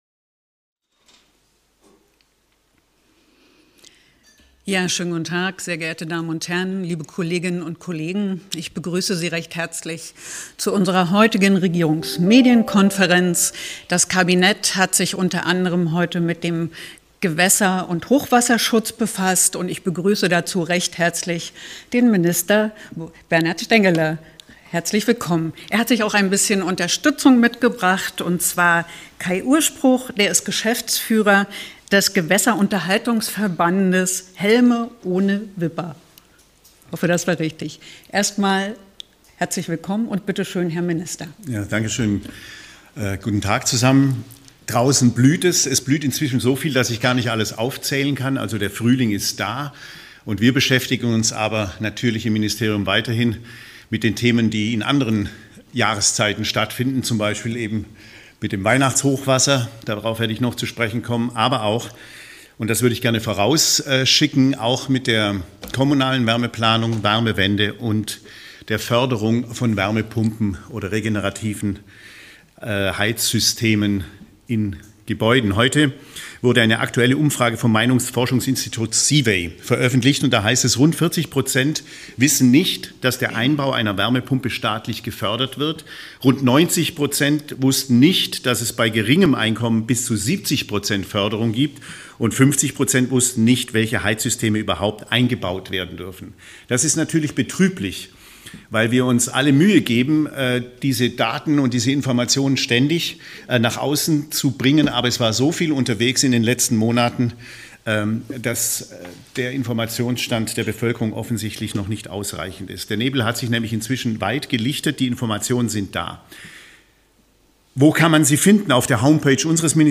Gewässerunterhaltung in Thüringen erfolgreich ~ Regierungsmedienkonferenz des Freistaats Thüringen Podcast